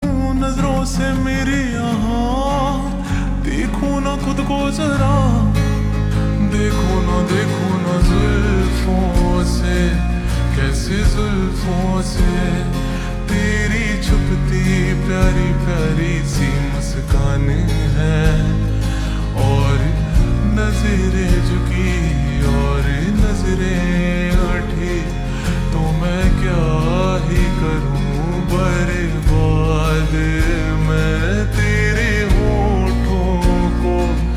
( Slowed + Reverb)